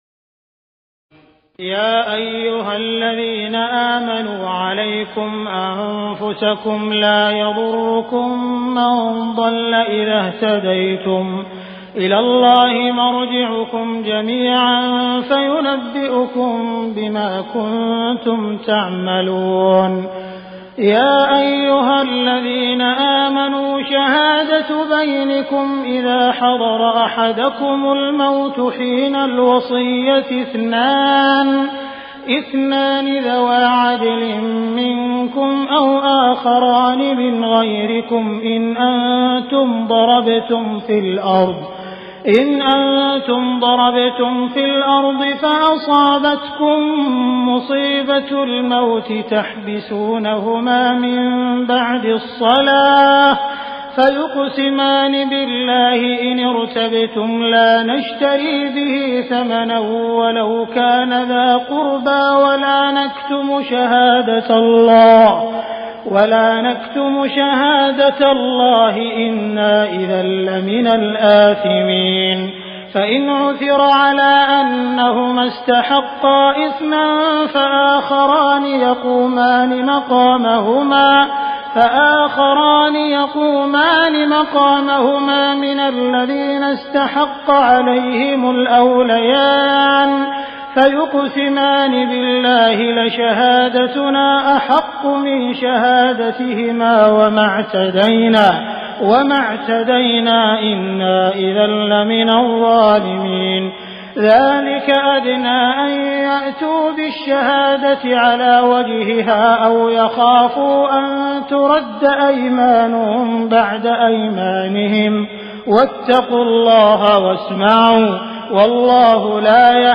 تراويح الليلة السابعة رمضان 1418هـ من سورتي المائدة (105-120) و الأنعام (1-73) Taraweeh 7 st night Ramadan 1418H from Surah AlMa'idah and Al-An’aam > تراويح الحرم المكي عام 1418 🕋 > التراويح - تلاوات الحرمين